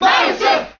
File:Marth Cheer Japanese SSBM.ogg
Marth_Cheer_Japanese_SSBM.ogg